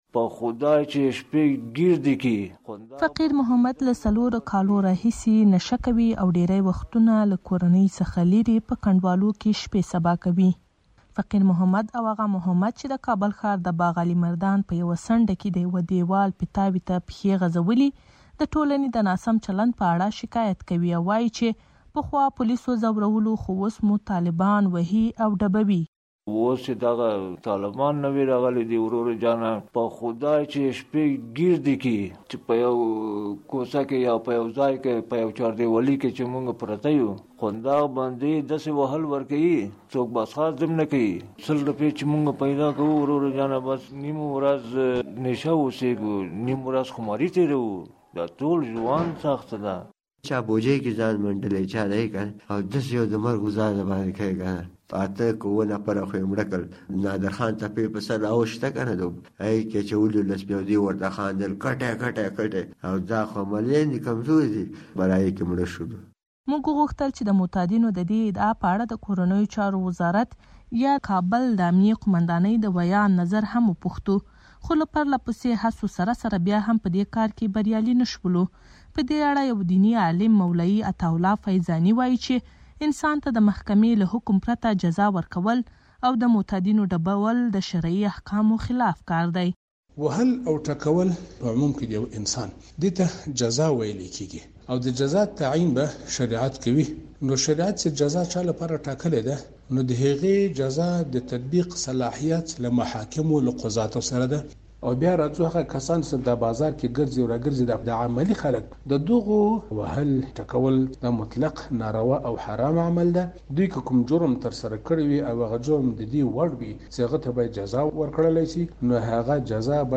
فیچر